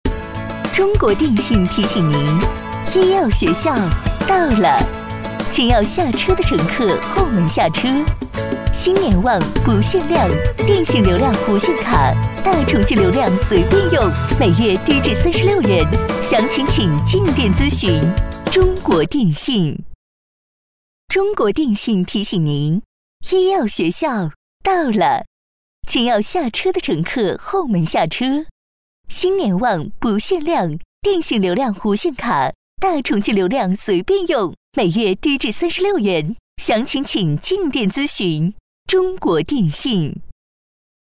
15 女国122_多媒体_广播提示_车辆报站中国电信 女国122
女国122_多媒体_广播提示_车辆报站中国电信.mp3